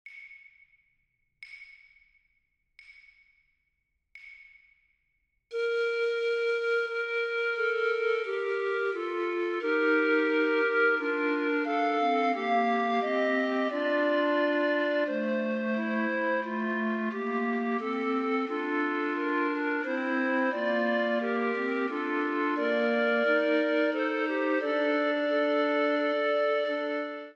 TTB recorders
A Dutch composer active in Denmark and Sweden, Baston layers expressive counterpoint over the graceful contours of the original. One especially moving moment comes with the line “Mal soudain recommence” (“Sudden evil returns”), where a poignant clash between voices evokes a musical sigh—a hesitation that mirrors the emotional weight of the text.